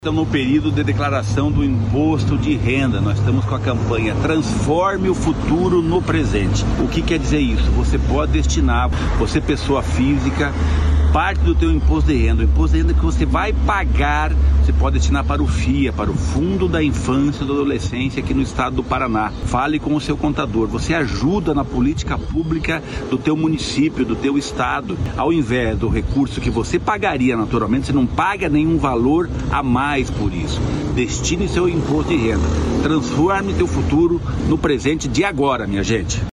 Sonora do secretário do Desenvolvimento Social e Família, Rogério Carboni, sobre a possibilidade de doar parte do pagamento do IR para o Fundo da Infância e Adolescência